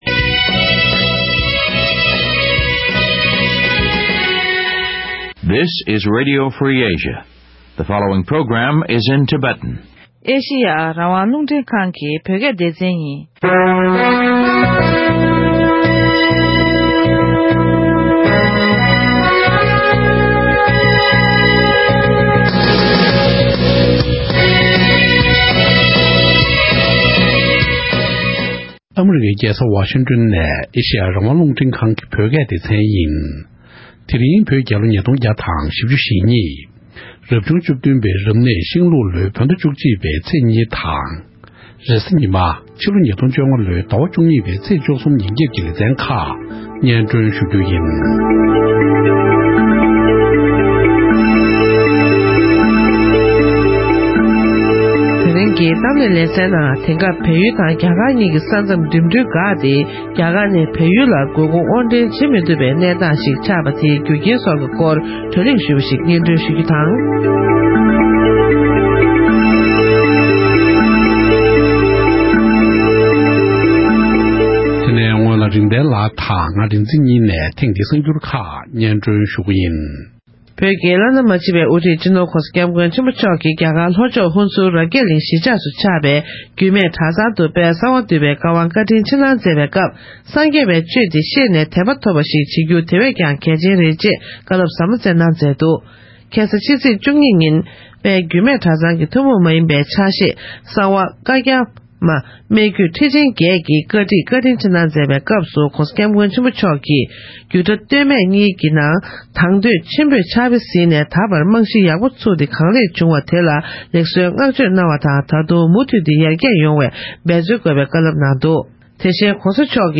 འབྲེལ་ཡོད་མི་སྣར་གླེང་མོལ་ཞུས་པ་ཞིག